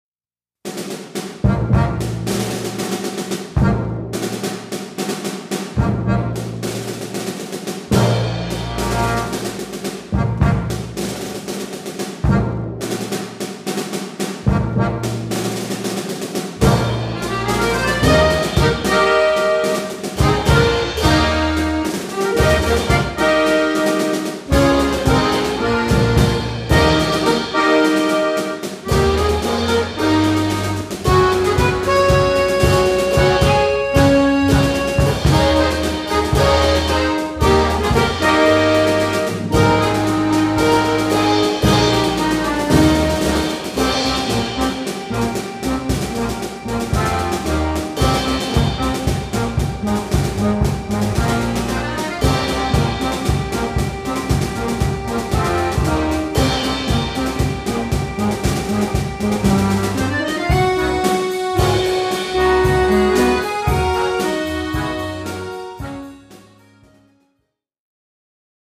Recueil pour Accordéon - Orchestre d'Accordéons